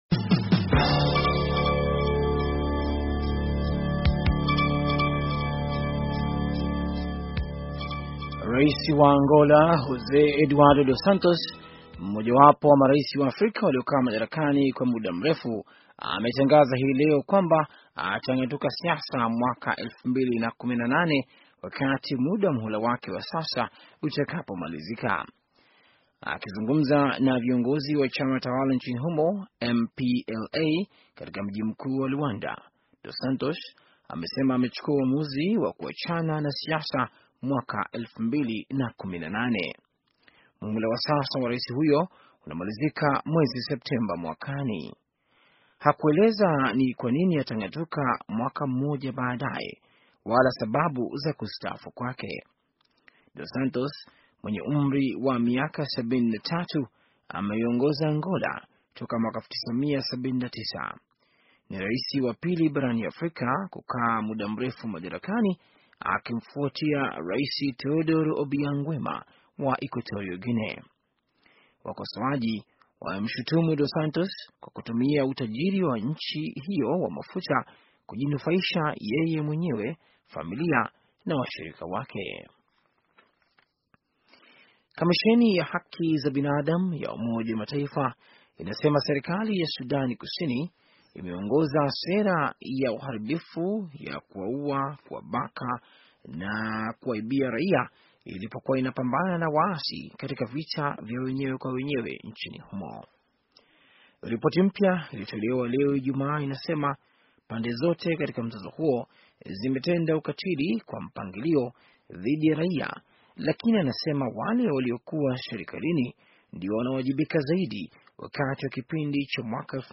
Taarifa ya habari - 6:55